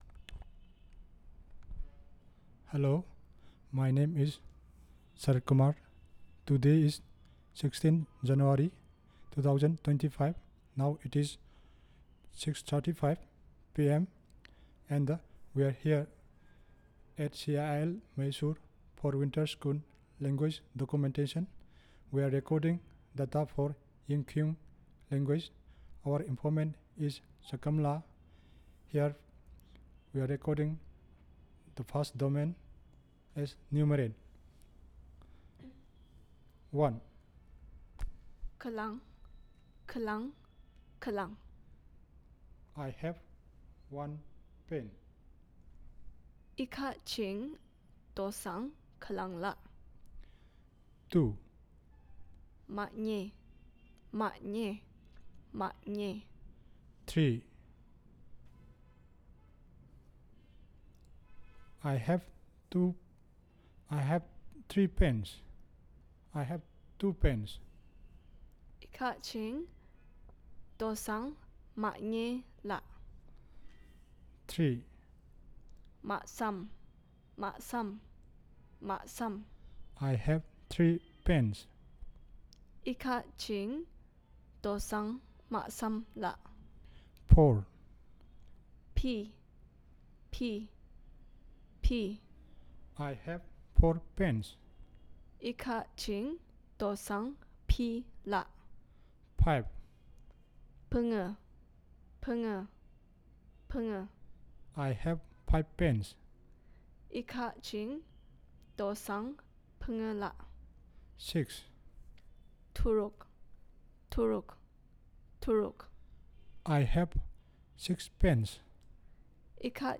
Elicitation of multiple domain words related to Numerals, Animals and Plants